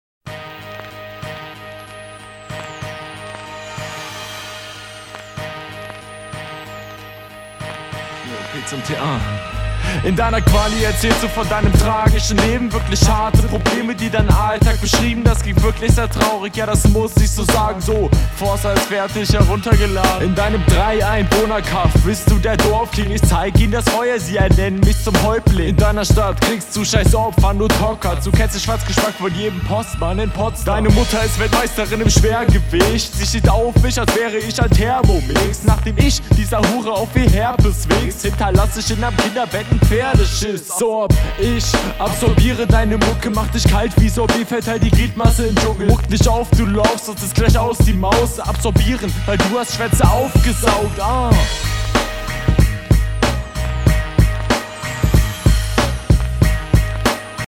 kommt gut rein. flow mehr verspielt diesmal. thermomix line funnyyy mit der feuer line. hat …